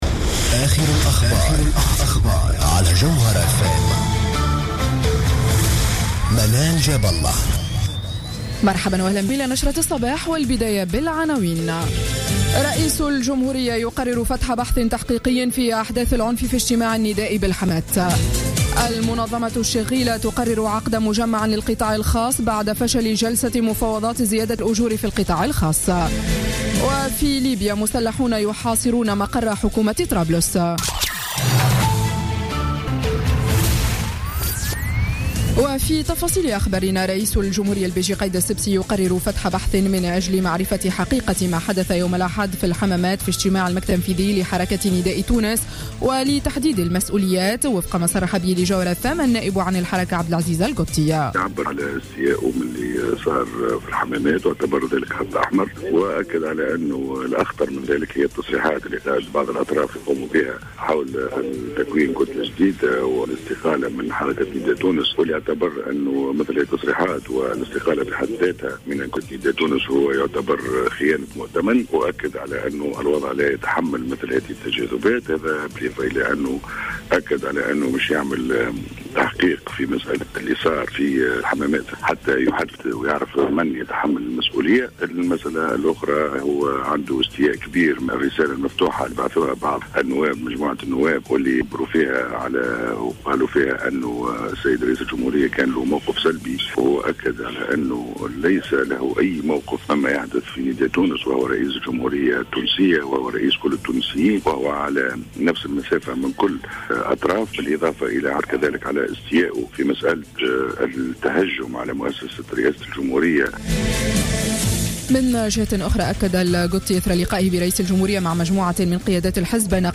نشرة أخبار السابعة صباحا ليوم الثلاثاء 3 نوفمبر 2015